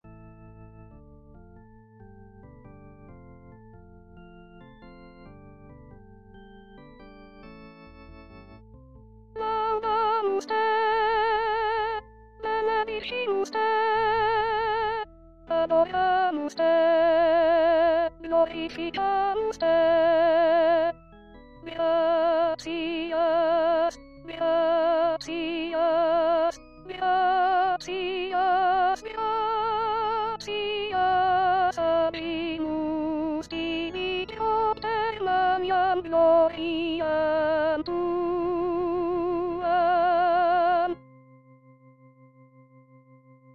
Chanté:     S1